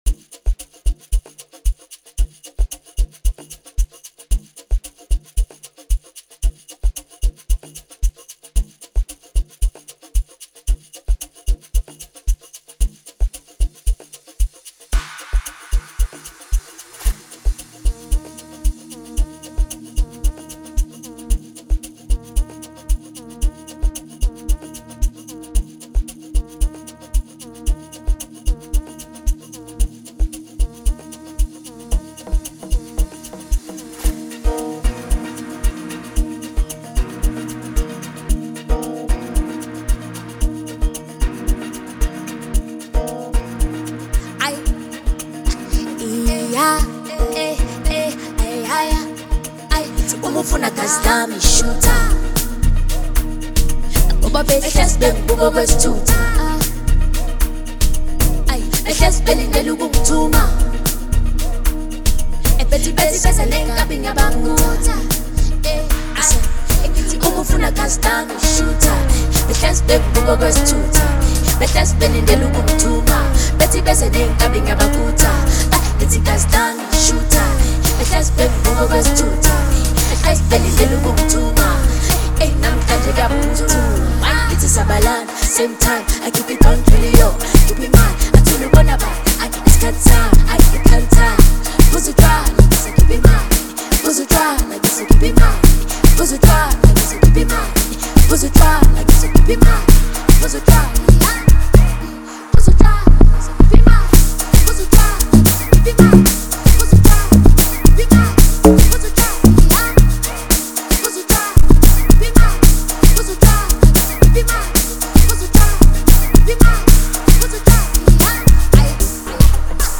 delivering a smooth and melodic performance.
spitting some fire and clever wordplay.